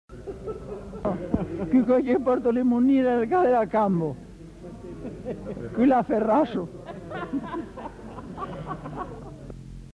Aire culturelle : Haut-Agenais
Genre : forme brève
Type de voix : voix de femme
Production du son : récité
Classification : devinette-énigme